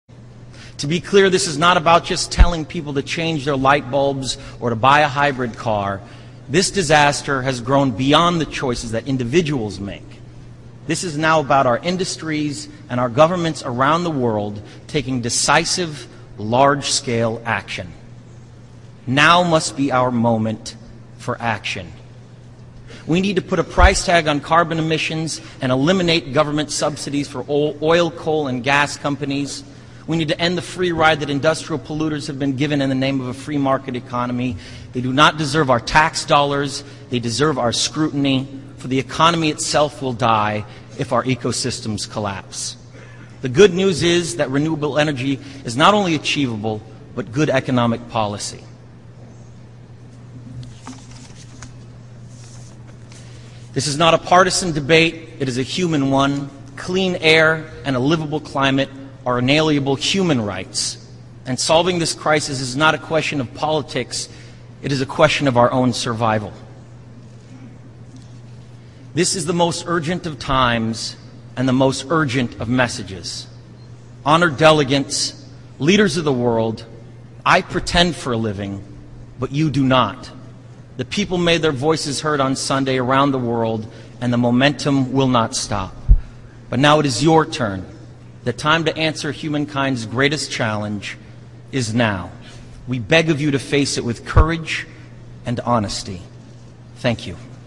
欧美名人演讲 第43期:莱昂纳多气候峰会演讲(2) 听力文件下载—在线英语听力室